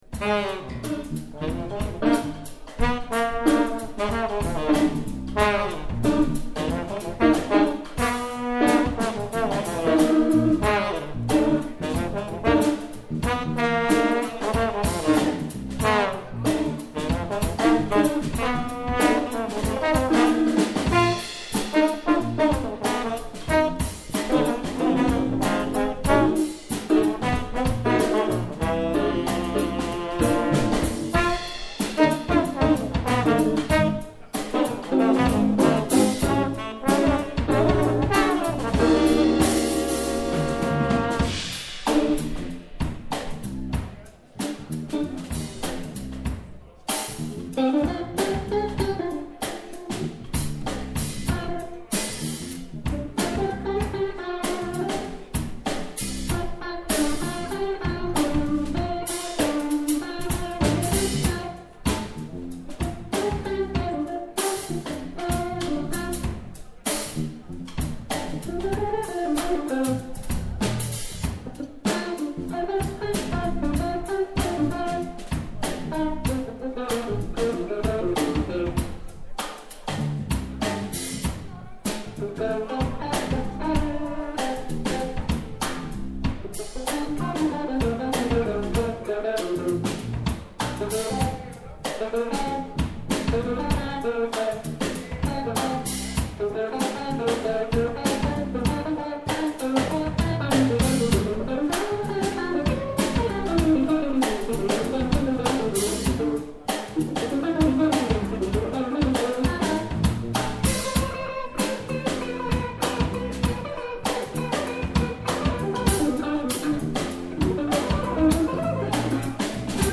live in der Sandgrube 25 in Speikern am 16.7.2022
saxophone
trombone
guitar
bass
drums